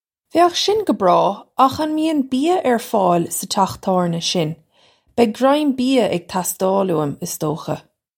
Pronunciation for how to say
Vay-ukh shin guh braw akh un mee-un bee-ya urr foyl suh chakh tawrnya shin? Beg grime bee-ya ig tas-toil oo-im, iss doh-kha.
This is an approximate phonetic pronunciation of the phrase.